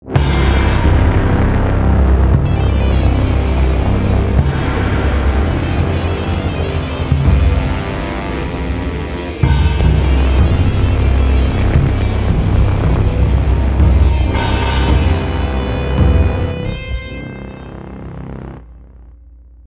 Storm_op_zee.mp3